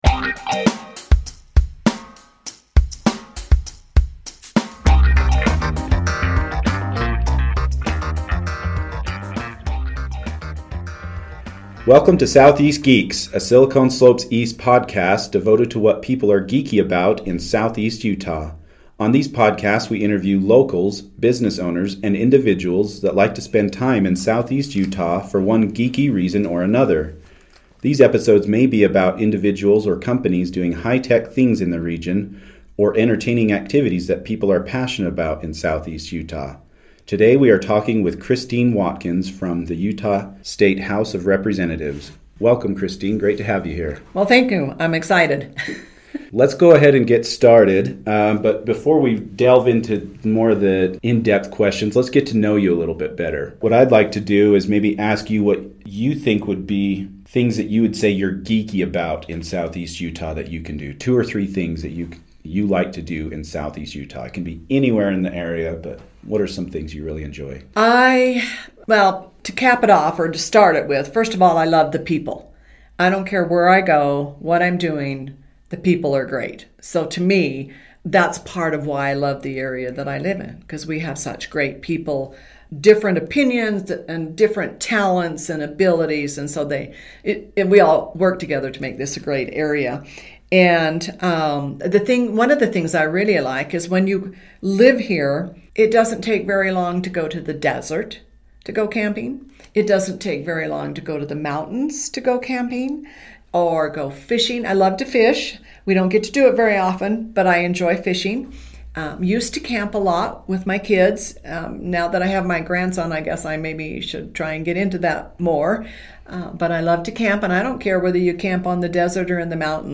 Interview With State Representative Christine Watkins